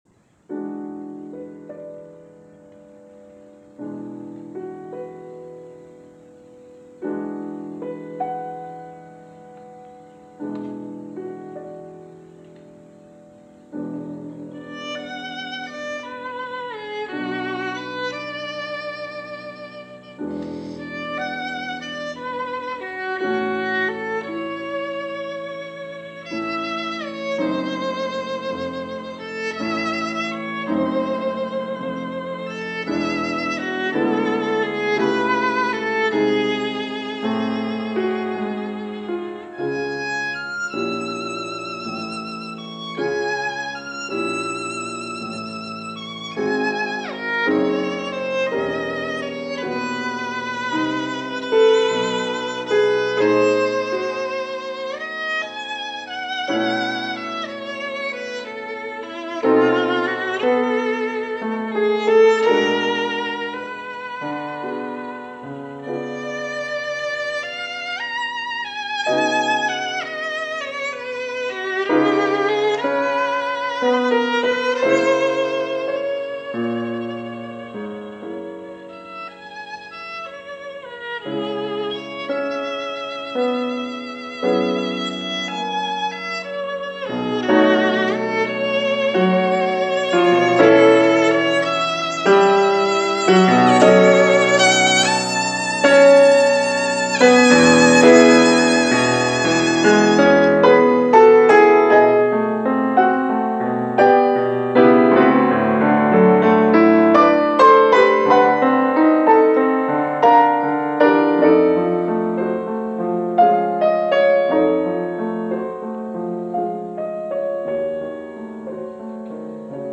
Cesar Franck – Sonata for Violin and Piano – Jean-Pierre Wallez, Violin
ORTF Broadcast 1963